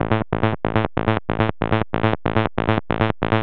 SNTHBASS044_DANCE_140_A_SC3.wav